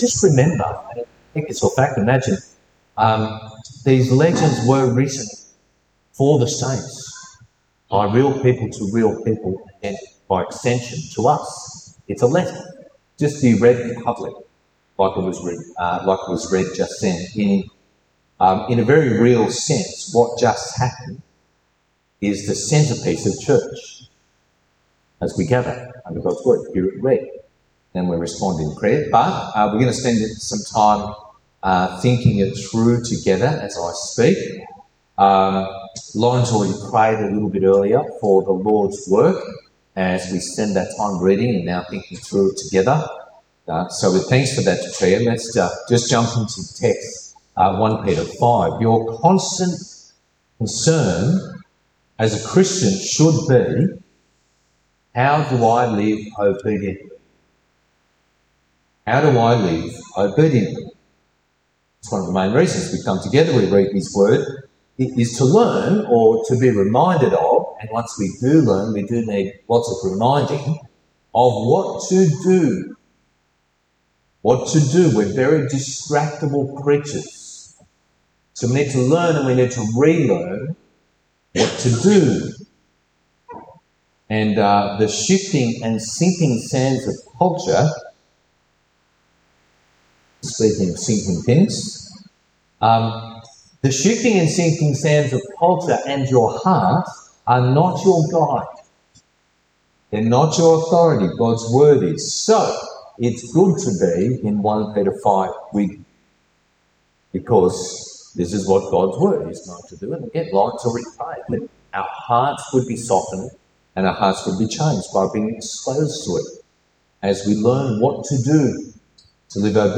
As followers of Christ, we are called to clothe ourselves with humility. How can we resist pride and be humble towards each other? Find out more in the sermon.